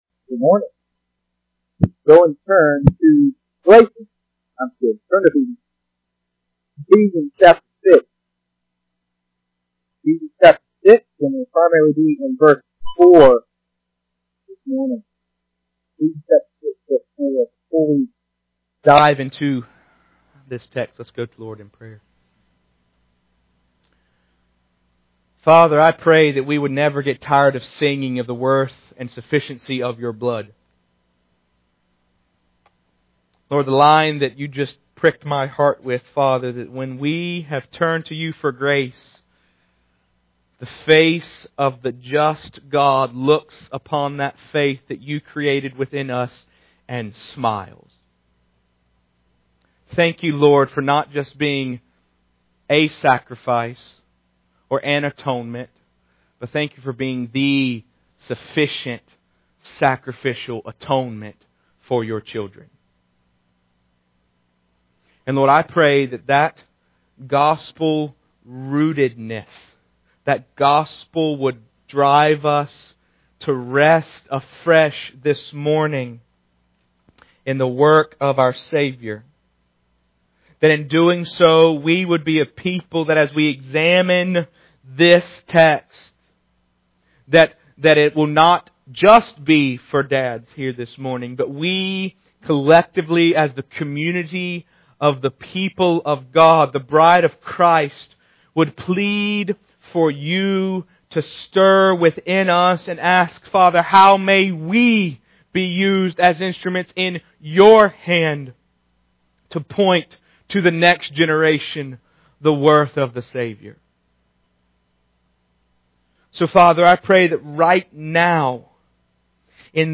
(Note: The audio is extremely muted for the first 20 seconds or so, but should sound normal after that.)